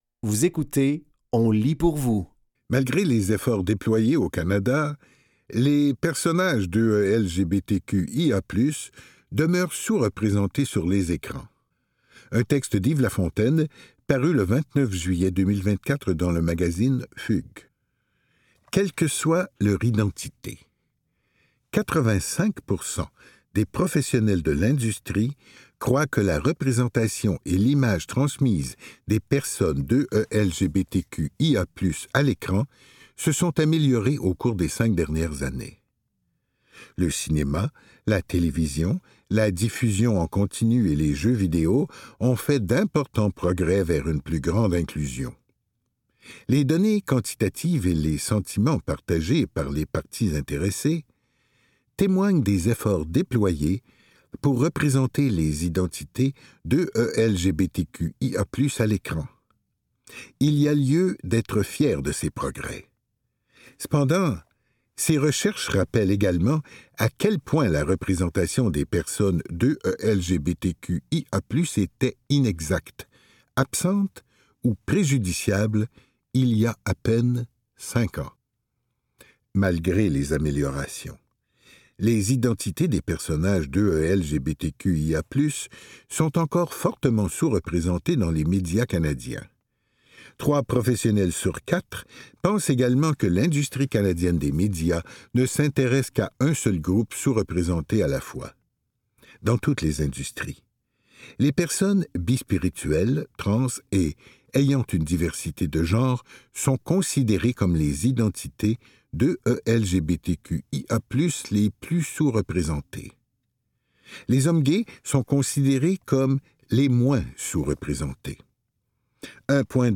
Dans cet épisode de On lit pour vous, nous vous offrons une sélection de textes tirés des médias suivants : Le Soleil, La Presse et Ça m'intéresse. Au programme: Malgré les efforts déployés au Canada, les personnages 2ELGBTQIA+ demeurent sous-représentés sur les écrans, un texte d'Yves Lafontaine, paru le 29 juillet 2024 dans Fugues.